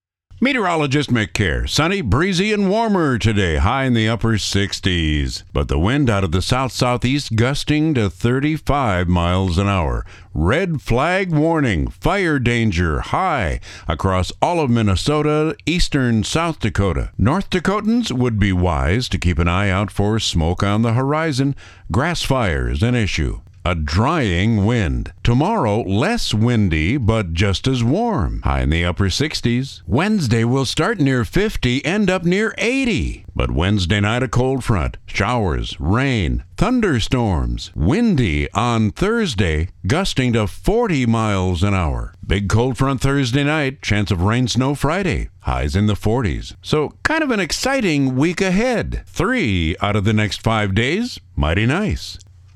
Weather Forecast